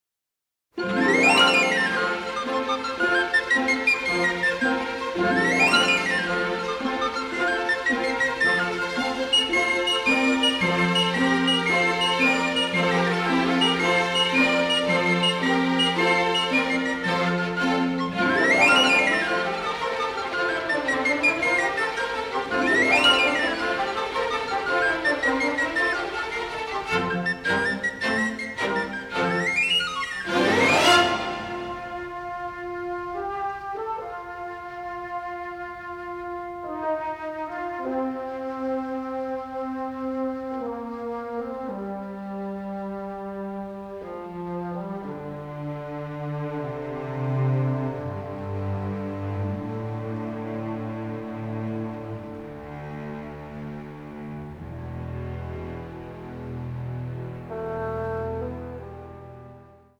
a sensitive, dramatic, delicate score with an Irish flavor